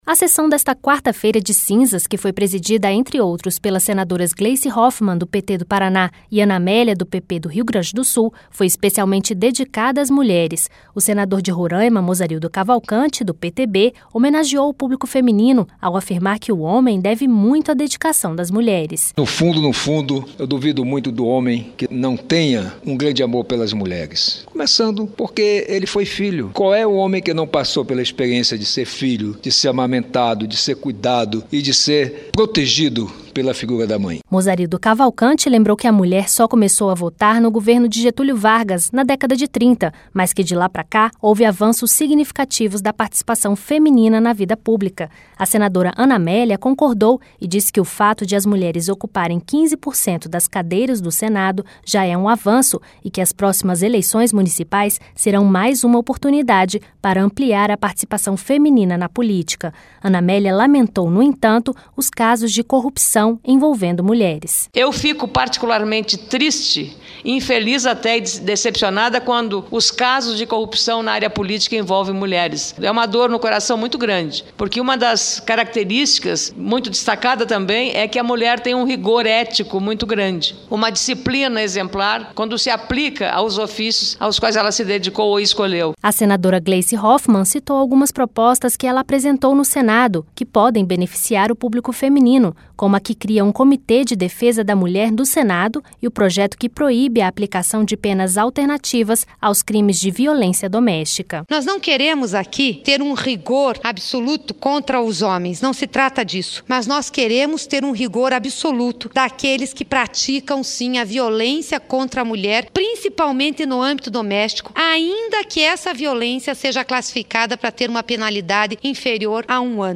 A sessão desta quarta-feira de cinzas, que foi presidida, entre outros, pelas senadoras Gleisi Roffmann, do PT do Paraná, e Ana Amélia, do PP do Rio Grande do Sul, foi especialmente dedicada às mulheres.